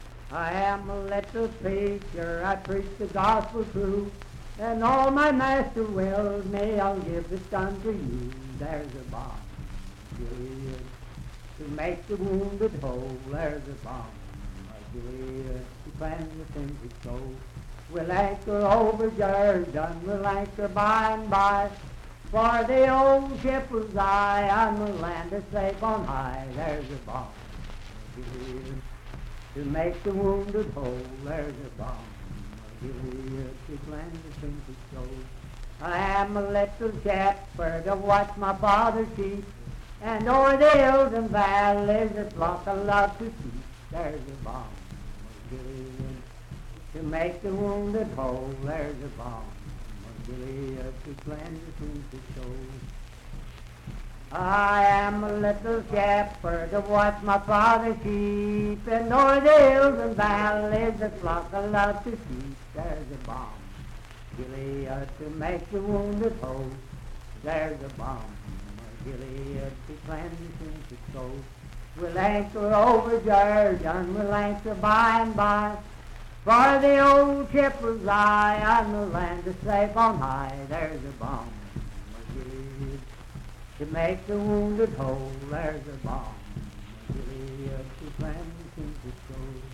Unaccompanied vocal music and folktales
Hymns and Spiritual Music
Voice (sung)